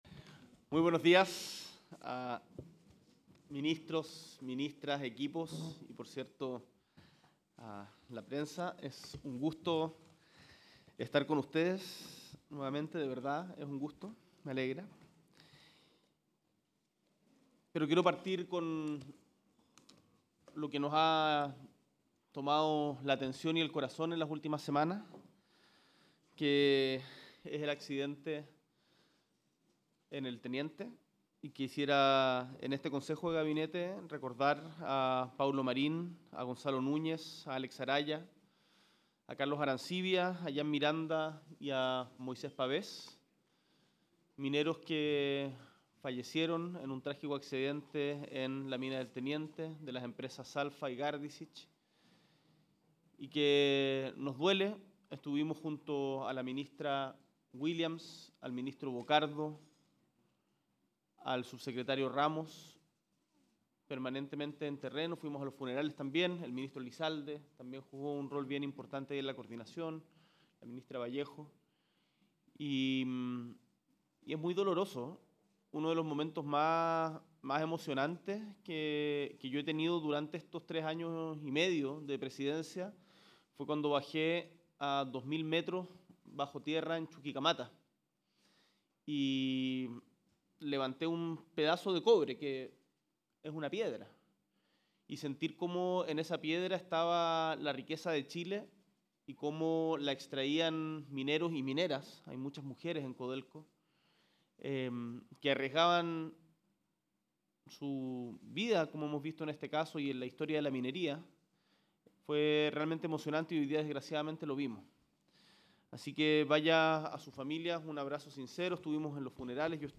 Este viernes 8 de abril, en el Salón Montt Varas del Palacio de La Moneda, el Presidente Gabriel Boric Font, encabezó un nuevo consejo de gabinete junto a sus ministros y ministras de Estado.